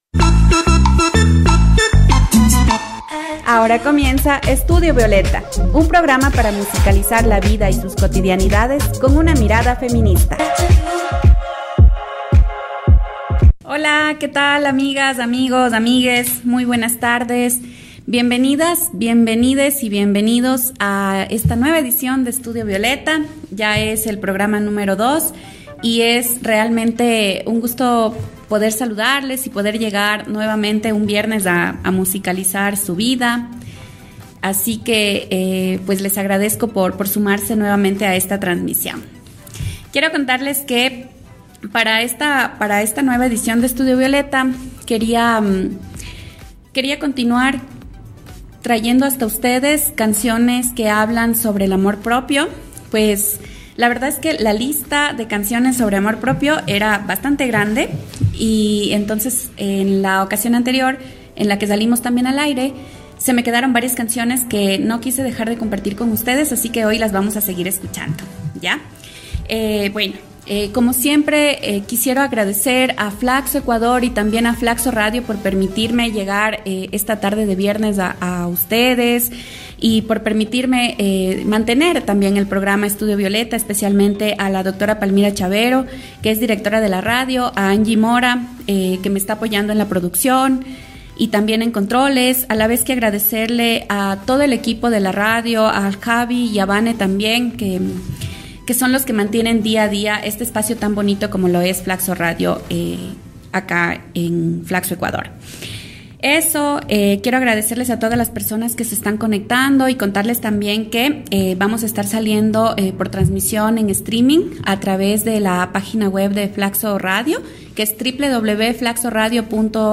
Amor propio ¿un privilegio? 60 minutos de diálogos y música feminista- Studio Violeta | FLACSO Radio